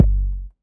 Kick 808) 1_2.wav